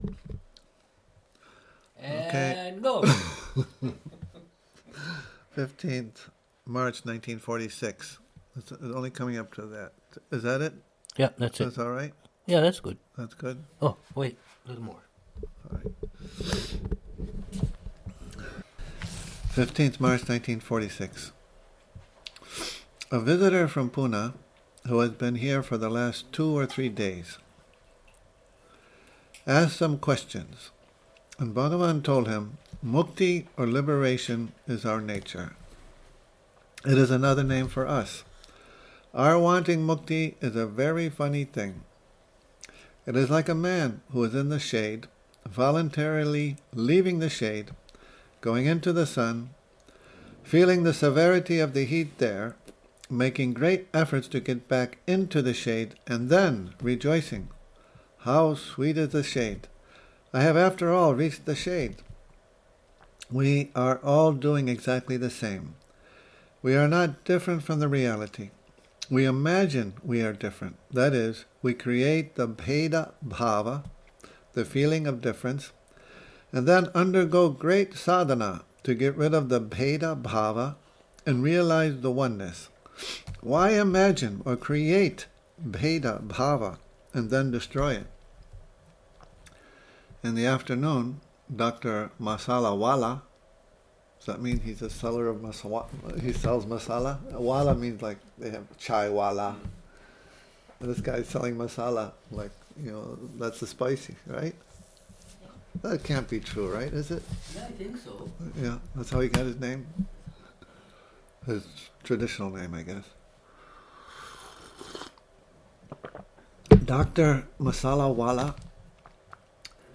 a morning reading in the Ashrama